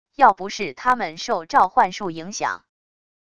要不是他们受召唤术影响wav音频生成系统WAV Audio Player